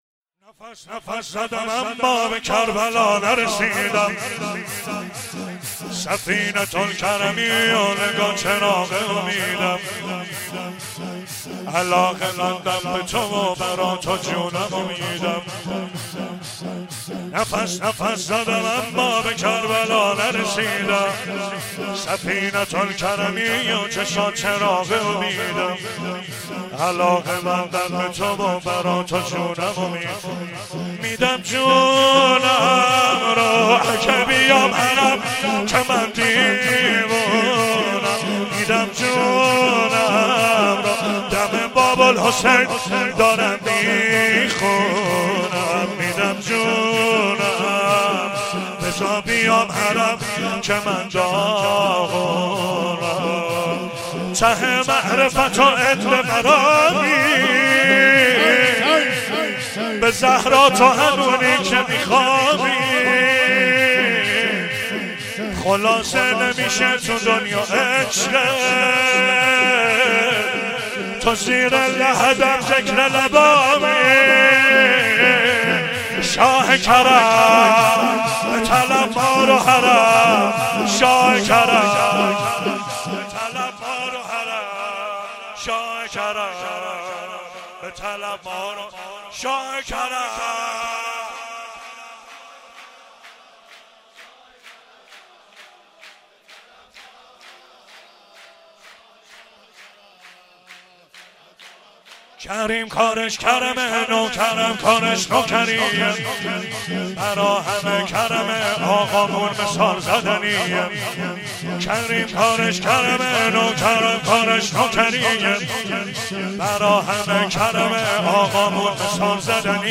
سرود مولودی